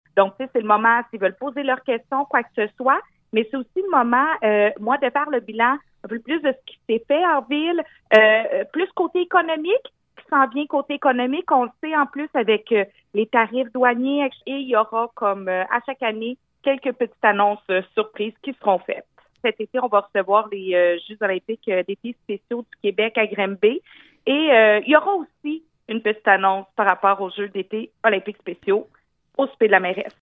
Nouvelles
La mairesse Julie Bourdon :